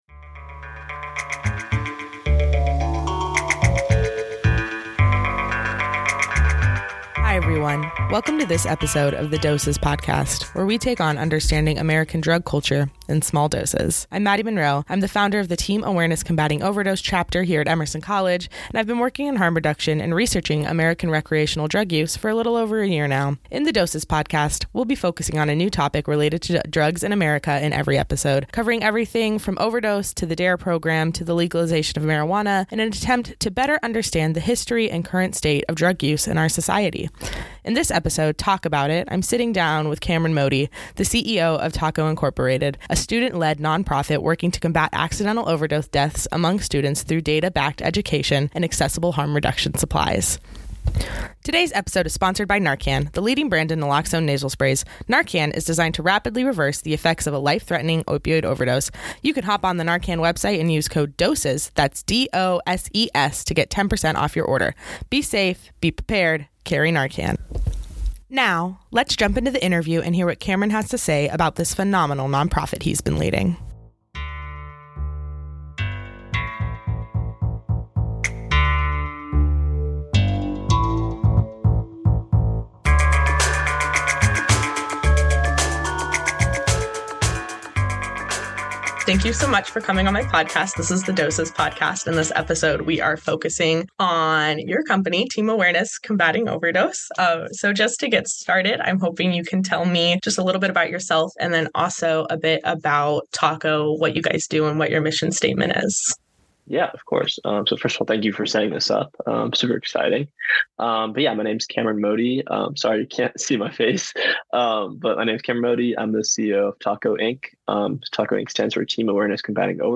Interview begins